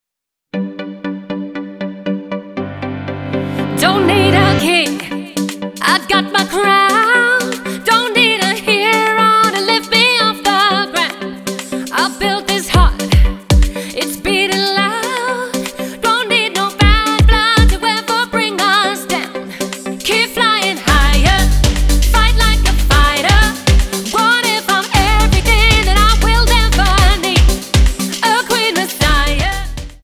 --> MP3 Demo abspielen...
Tonart:G Multifile (kein Sofortdownload.
Die besten Playbacks Instrumentals und Karaoke Versionen .